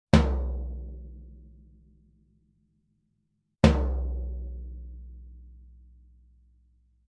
Das Standtom wurde wie im folgenden Bild zu sehen aufgebaut und mikrofoniert - zum Einsatz kamen zwei AKG C414B-ULS in einer ORTF-Anordnung, die direkt über ein Tascam DM24-Pult ohne weitere Bearbeitung mit 24 Bit in Samplitude aufgenommen wurden.
Einzelner Schlag - erst Vollgummi-, dann Air-Suspension-Füße
Diese Audio-Files sind absolut unbearbeitet belassen worden - also kein EQ, kein Limiter, kein Kompressor - nichts!